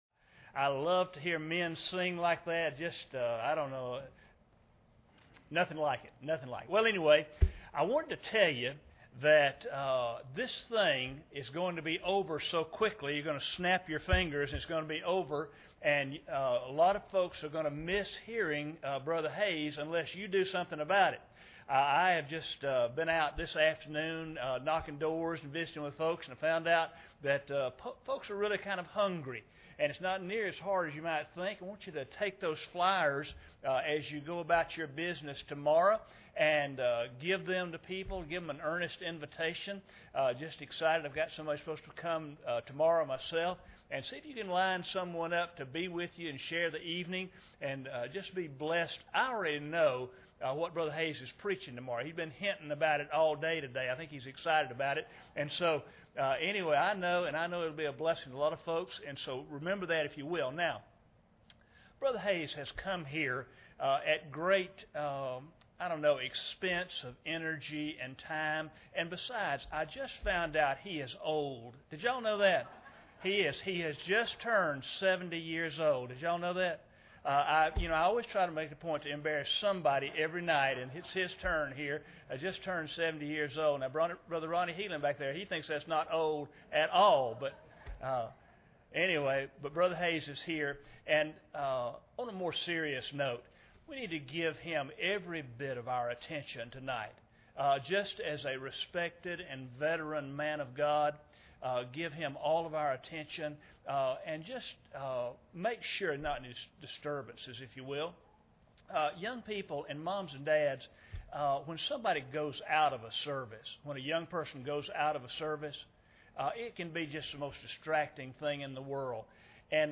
Jeremiah 33:3 Service Type: Revival Service Bible Text